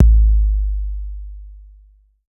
808 LITE K2.wav